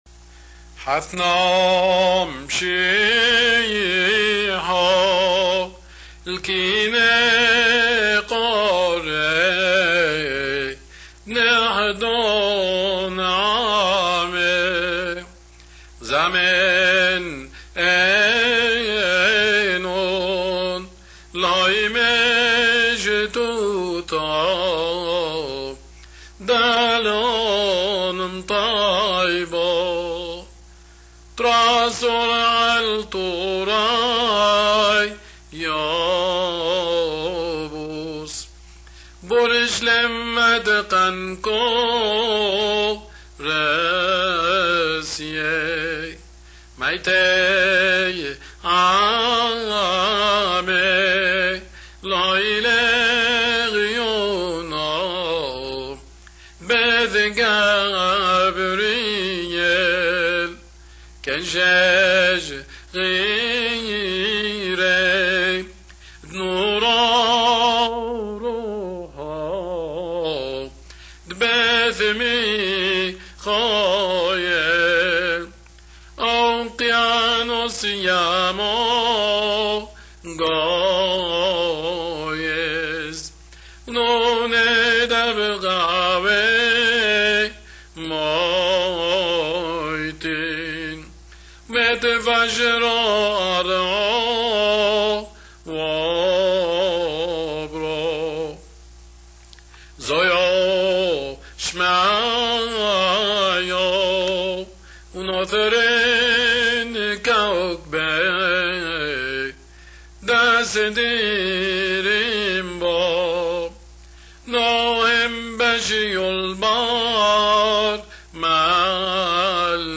Essa gravação foi, feita em fita magnética em 1961 aqui no Brasil e seu único intuito era o de ser um guia para estudos.
Músicas Sacras dos Primeiros Séculos do Cristianismo